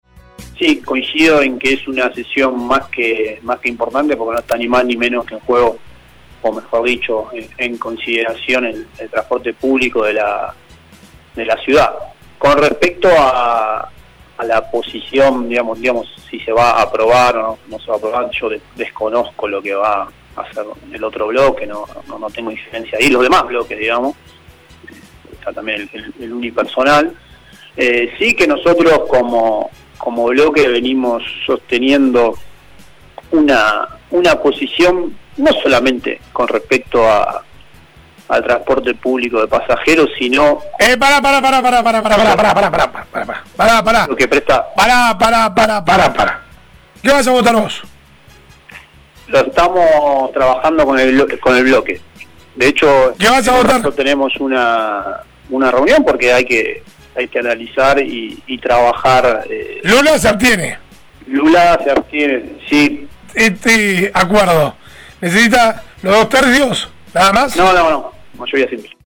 El concejal de Despierta Comodoro, Martín Gómez, le dijo a LA MAÑANA DE HOY que siguen trabajando con el pliego de licitación del servicio de Transporte Público. Todavía no puede adelantar si mañana votará a favor o en contra de la prórroga firmada ayer: